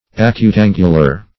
Acutangular \A*cut"an`gu*lar\, a.
acutangular.mp3